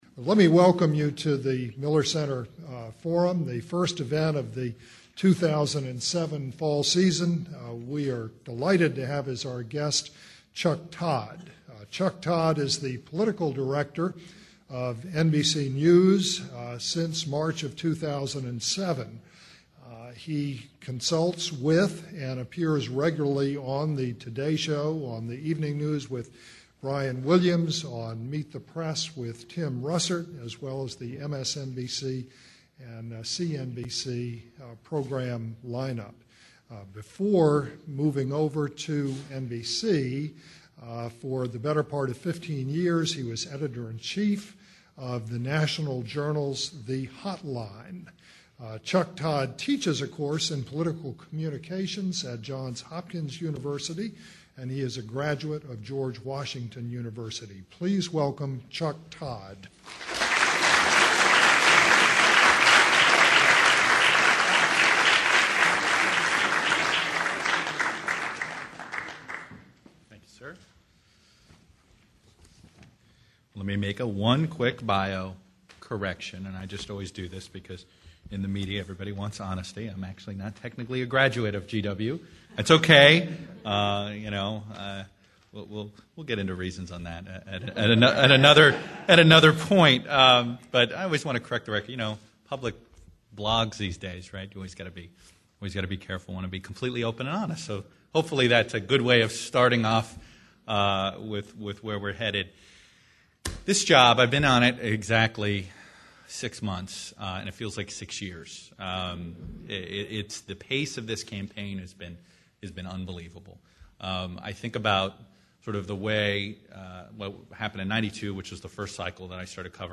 The presentation was made at a Miller Center Forum on March 20, 2009. http